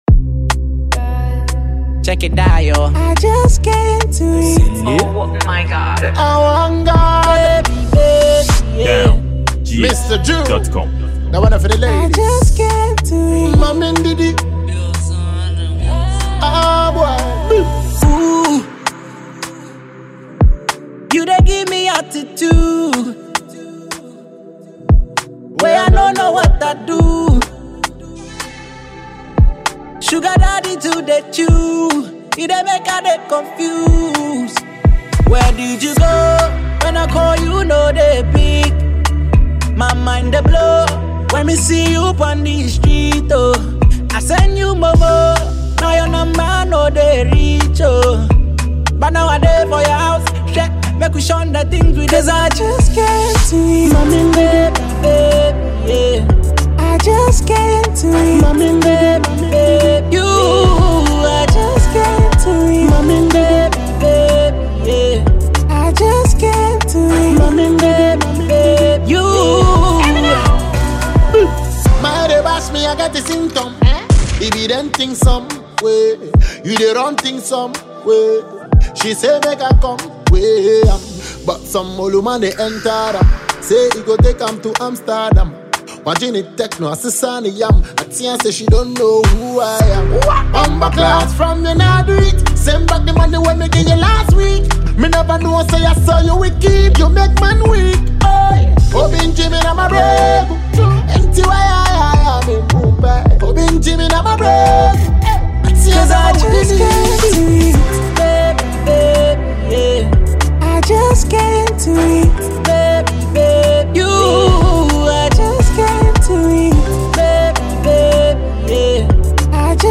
afrobeat dancehall